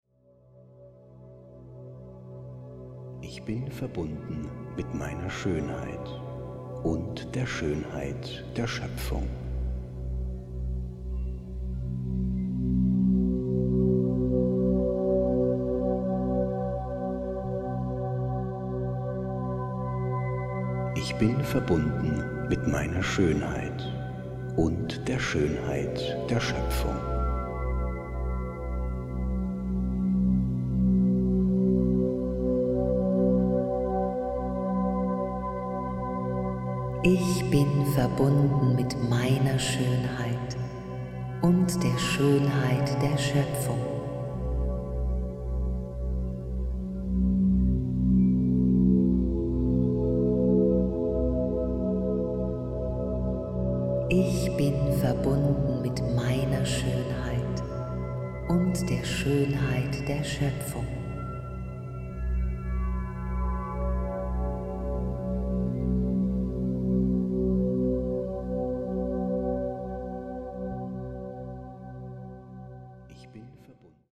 mit Tibetischen Klangschalen, Gongs und Keyboards unterlegt.